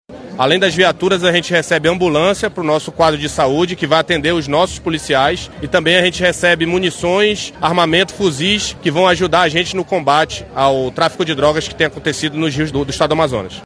O governo do Estado também fez a entrega de viaturas para fortalecer a Ronda Maria da Penha, programa de combate à violência contra as mulheres. O subcomandante Geral da Polícia Militar, Coronel Thiago Balbi, fala da importância desse reforço no patrulhamento ostensivo.
Sonora-Thiago-Balbi.mp3